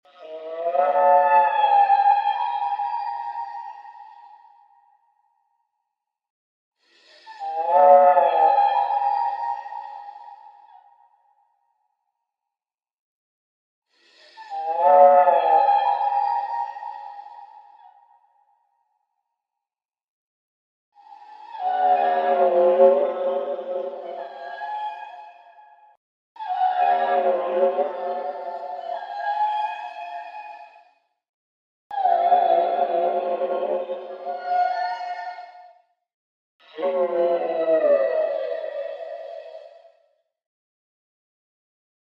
Jellyray sounds!